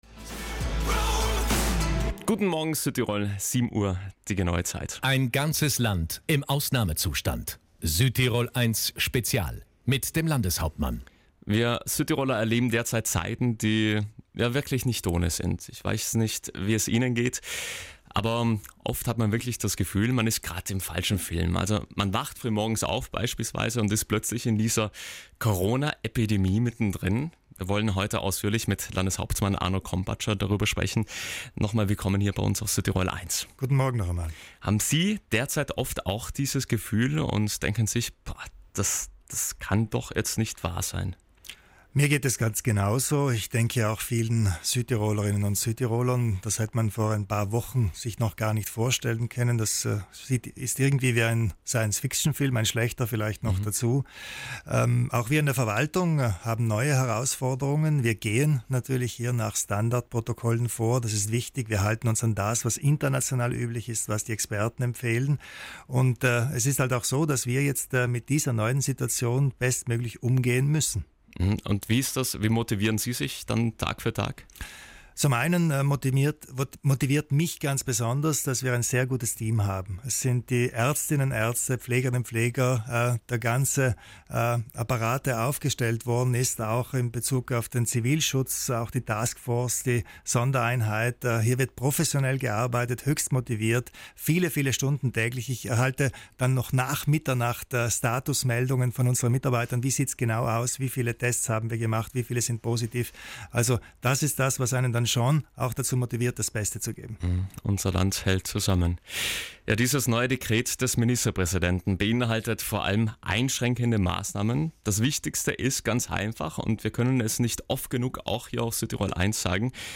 Landeshauptmann Arno Kompatscher hat Ihre Fragen zur Corona-Notverordnung in „Guten Morgen Südtirol" live auf Südtirol 1 beantwortet.